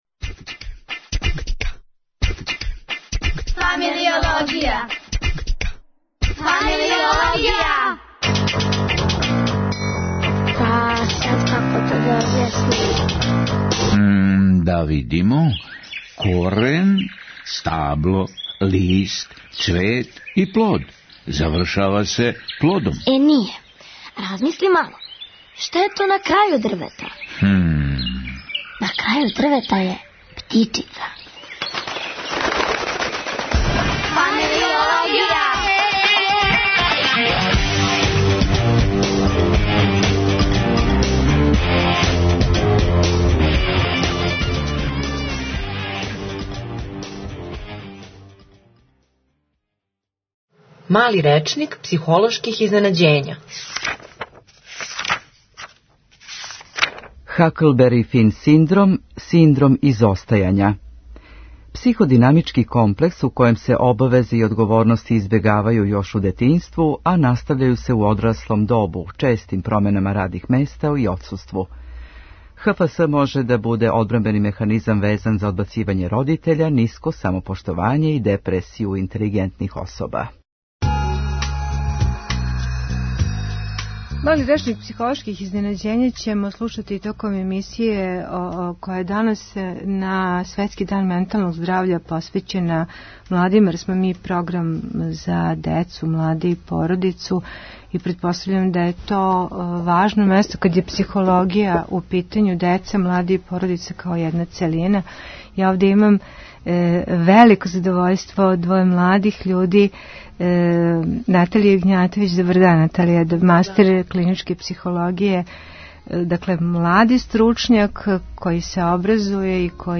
Искрена прича једне младе девојке која се (из)лечила од депресије...